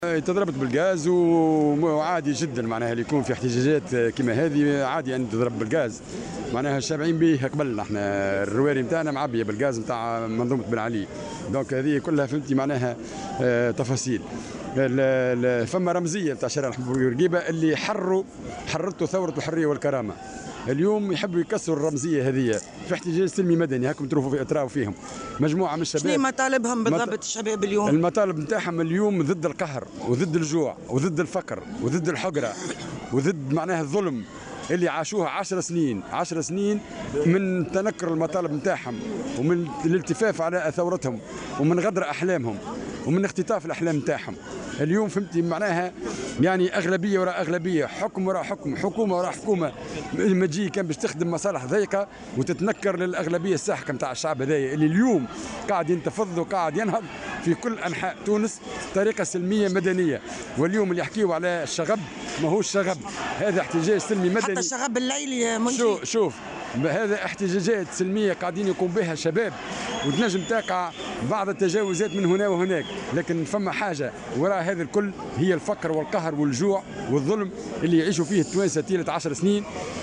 وقال في تصريح لمراسلة "الجوهرة أف ام" إن الاحتجاجات سلمية مدنية وهدفها التنديد بالتهميش والظلم والتنكّر لمطالب الشعب والالتفاف على الثورة، وفق تعبيره.